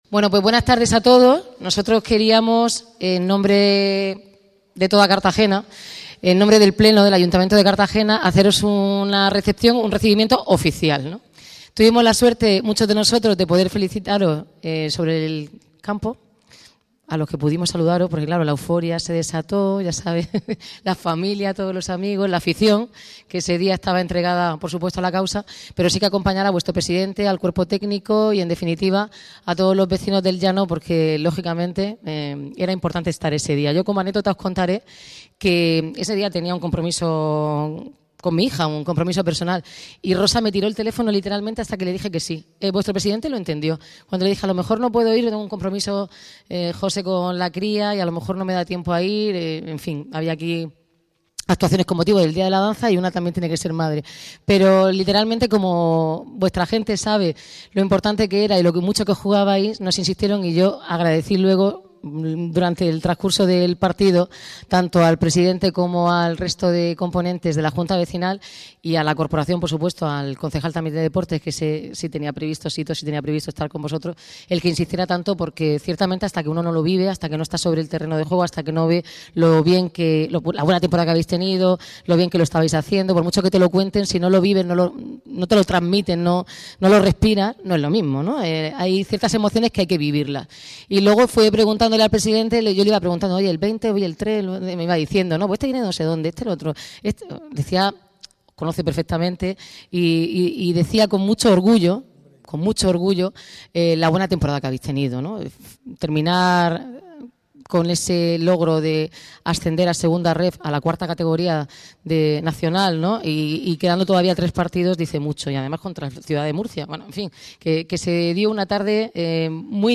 La alcaldesa de Cartagena, Noelia Arroyo, ha presidido este lunes 13 de mayo la recepción que el Ayuntamiento de Cartagena ha ofrecido en el Palacio Consistorial a la plantilla y cuerpo técnico del Club Deportiva Minera que asciende a Segunda RFEF, tras proclamarse líder del Grupo XIII de Tercera División.